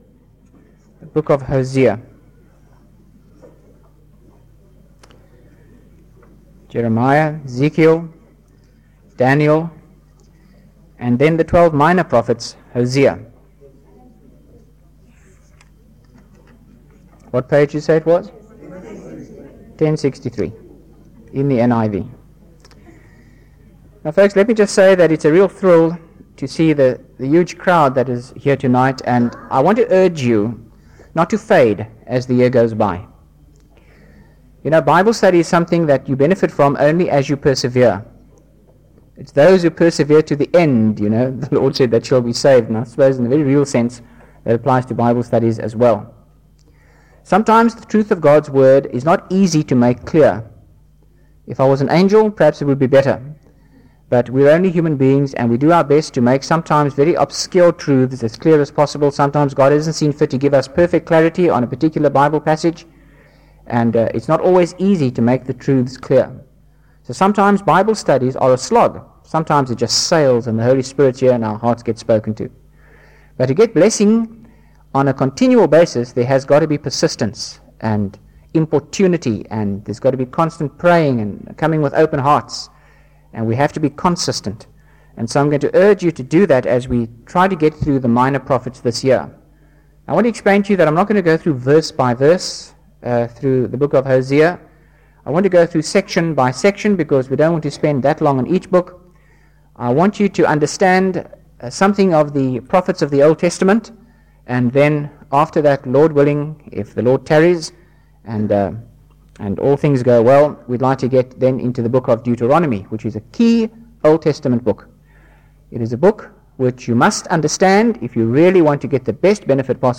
by Frank Retief | Jan 28, 2025 | Frank's Sermons (St James) | 0 comments